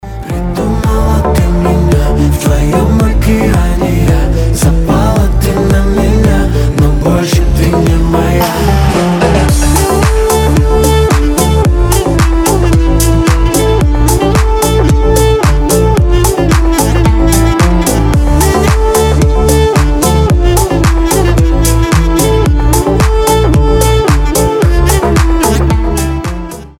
• Качество: 320, Stereo
поп
мужской вокал
deep house
восточные мотивы